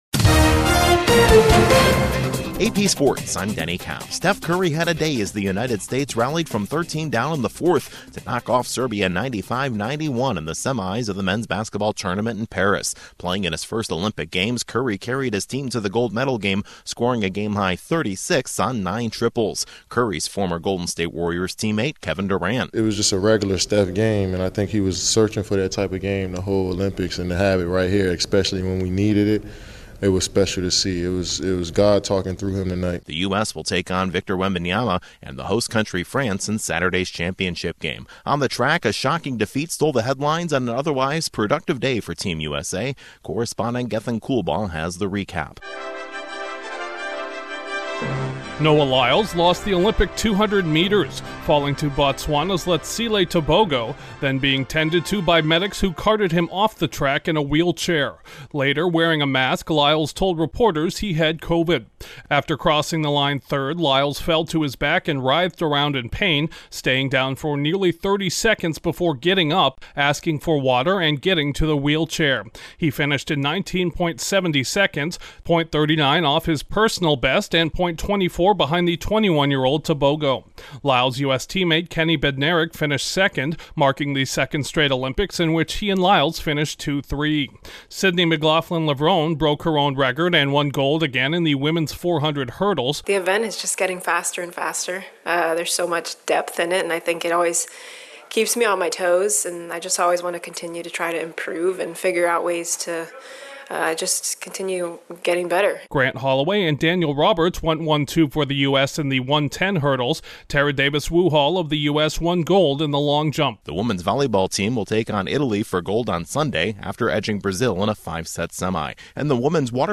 Sports News